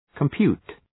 Προφορά
{kəm’pju:t}